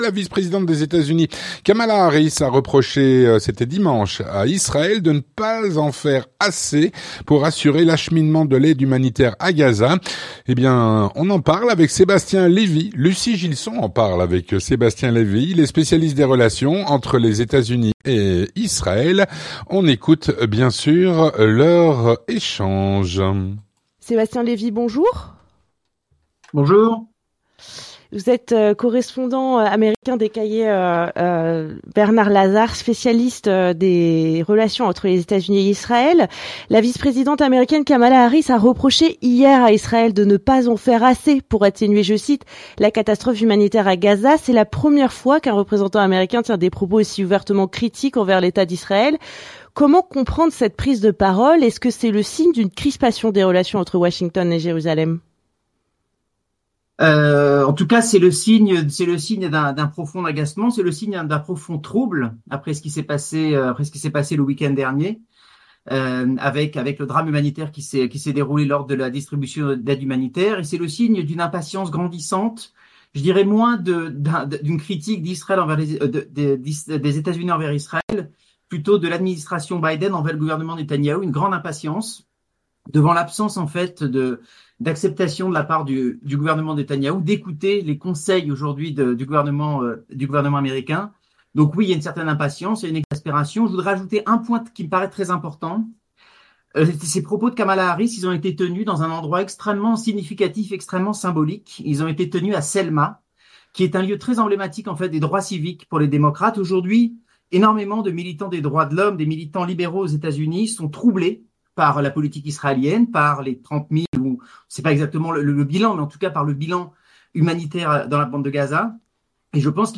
L'entretien du 18H - La vice-présidente des Etats-Unis, Kamala Harris, a reproché à Israël de ne pas faire assez pour assurer l’acheminement de l’aide humanitaire à Gaza.